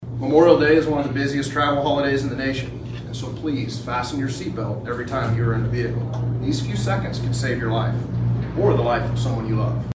The Kansas Department of Transportation officially launched the statewide “Click It or Ticket” campaign Wednesday at Manhattan’s Peace Memorial Auditorium.